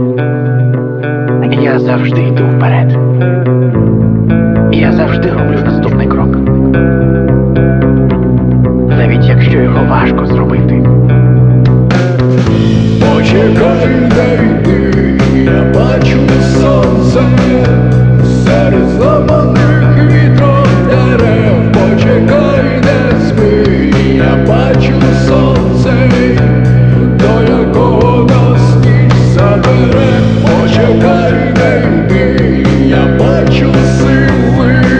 Жанр: Рок / Украинские